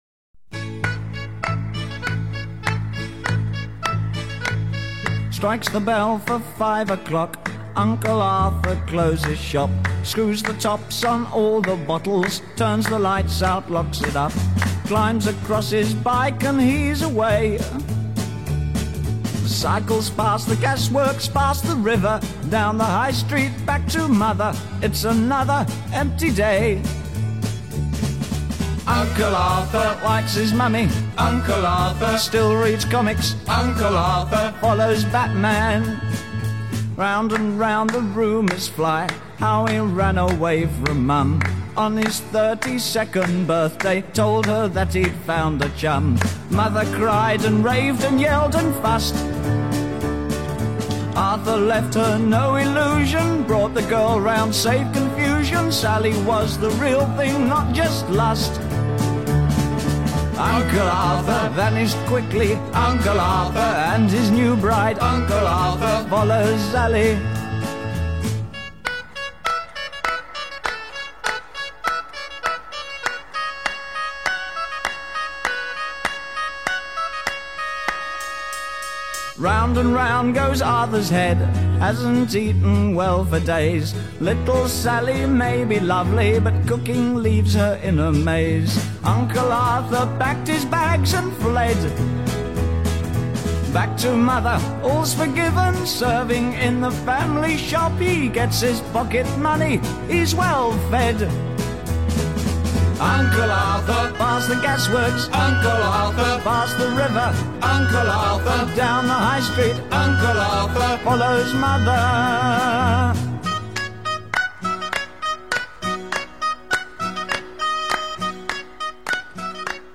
Baroque Pop / Psychedelic Pop / Art Rock